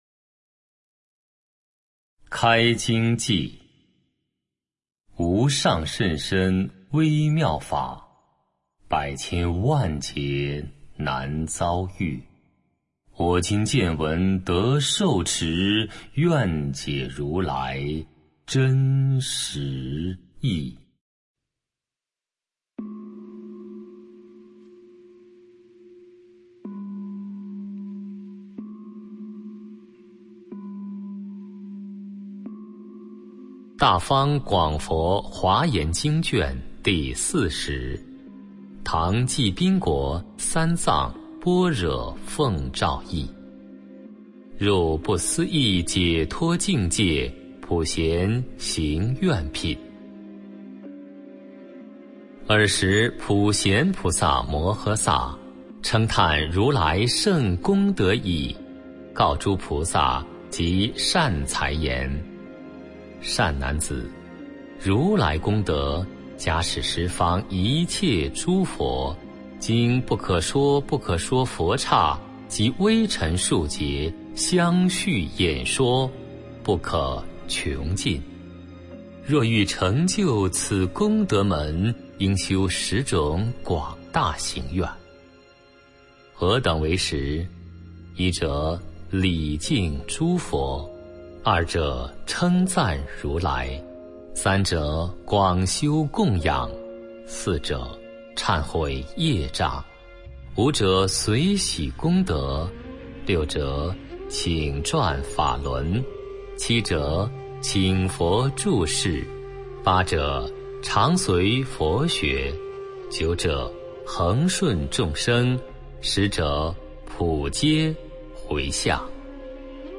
普贤行愿品读诵（本地音频） - 佛乐诵读
puxianxingyuanpin-songdu.mp3